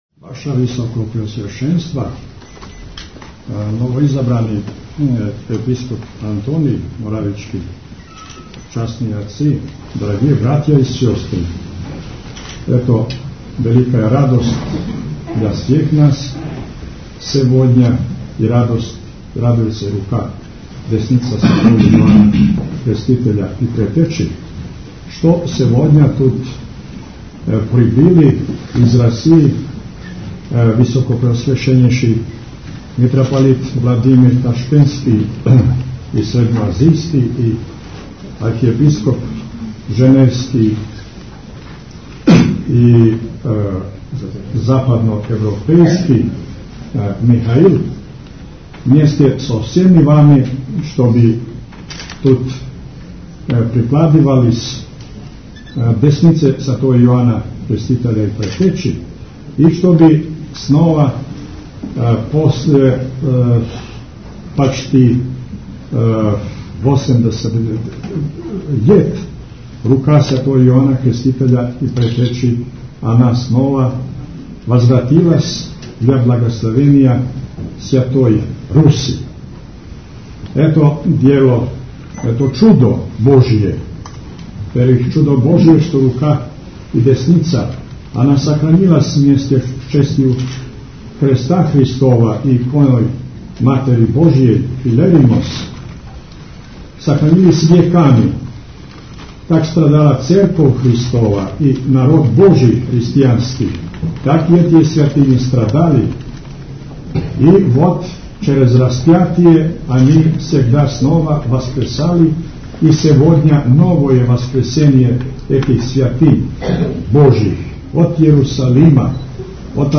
Митрополит Амфилохије-бесједа са молебана 6. јуна у Цетињском Манастиру
У Цетињском манастиру вечерас је тим поводом служен вечерњи молебан Светом Јовану Крститељу. Молебану је присуствовала руска Црквено-државна делегација која је данас допутовала у Црну Гору, а коју предводе Митрополит Ташкентско-средњеазијски РПЦ Господин Владимир и Митрополит Женевски Руске Заграничне Цркве Михаило.